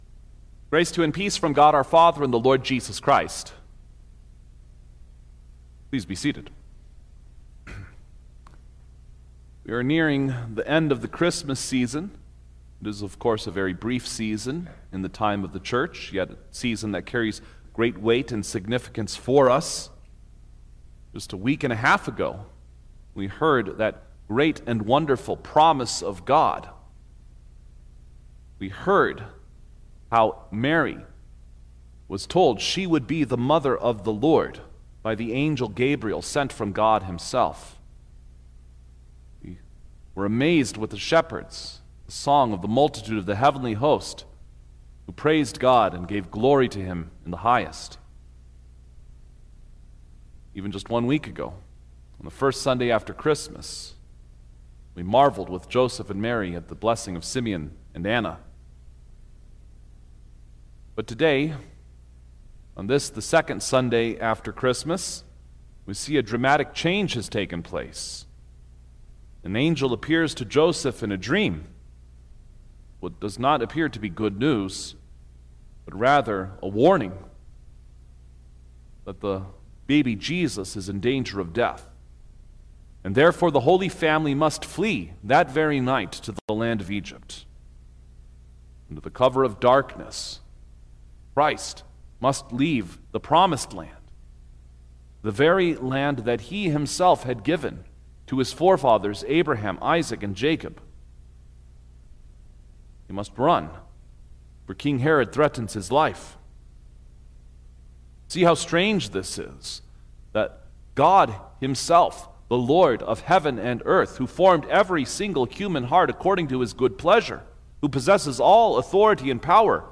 January-4_2026_Second-Sunday-after-Christmas_Sermon-Stereo.mp3